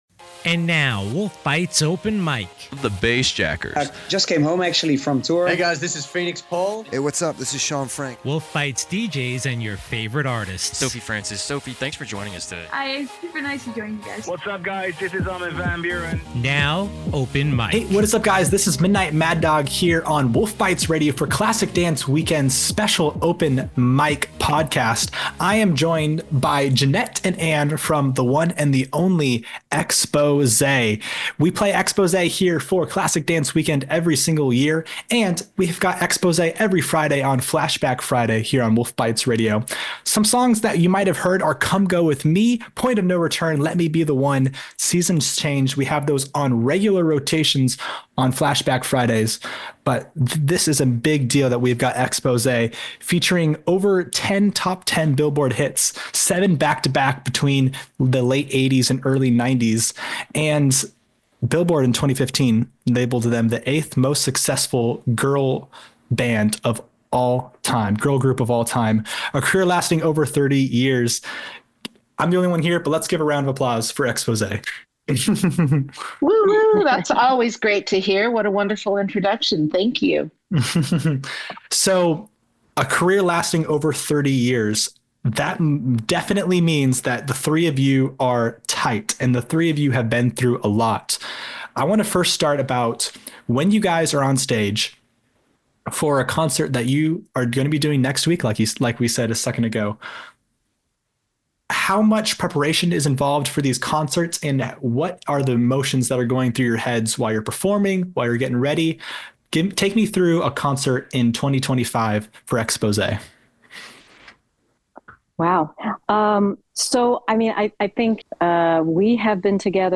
Interviews with the top performers today